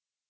silence.wav